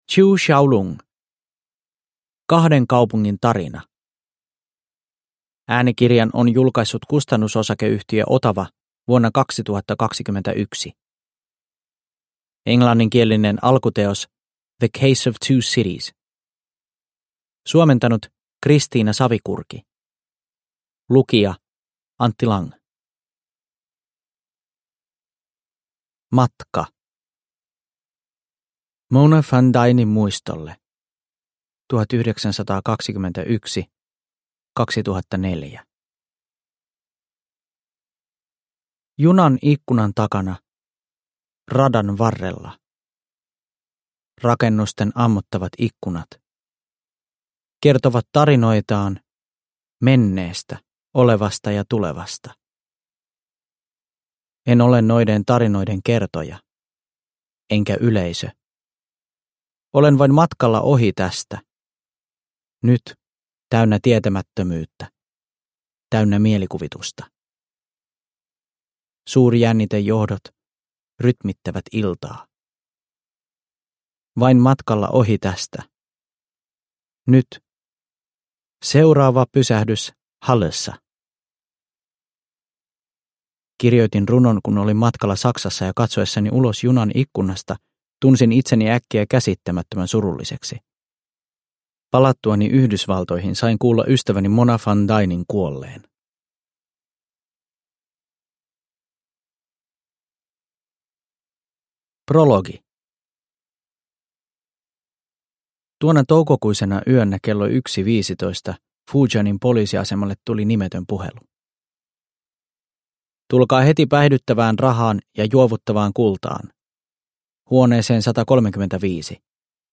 Produkttyp: Digitala böcker